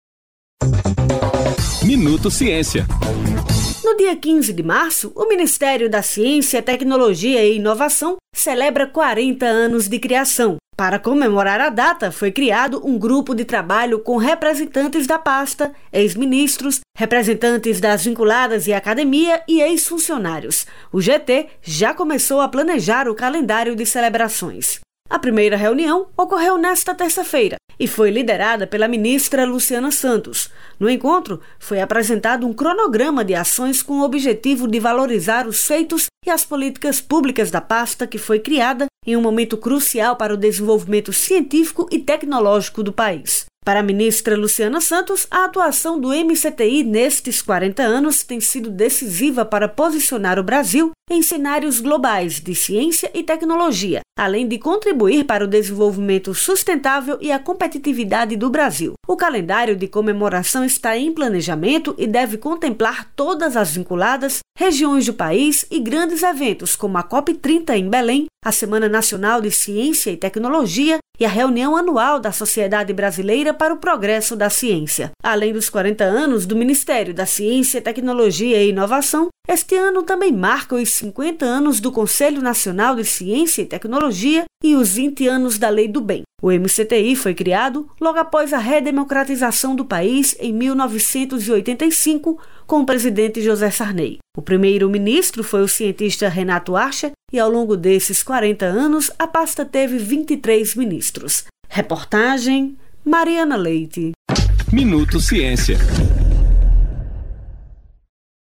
Boletim produzido pelo Ministério da Ciência, Tecnologia e Inovação (MCTI), com as principais informações do setor.